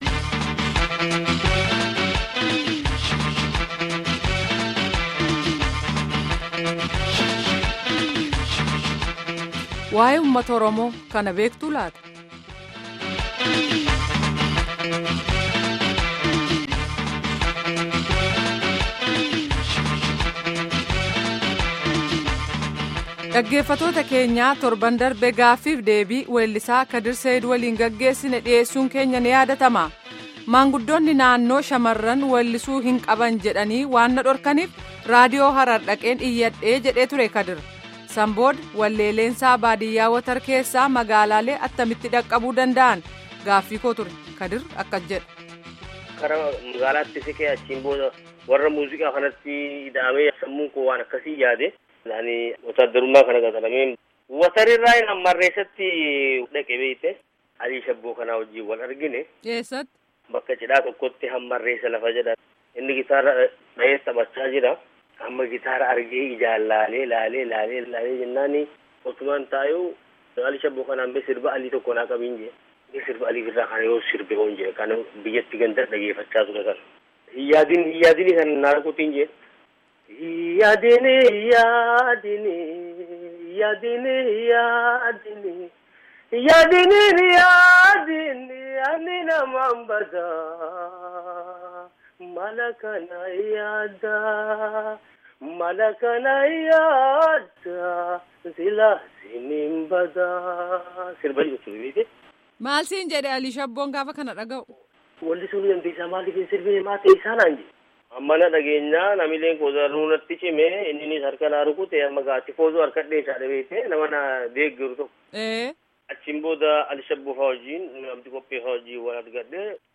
Embed share Gaaffii fi deebii kutaa 2ffaa by Sagalee Ameerikaa Embed share The code has been copied to your clipboard.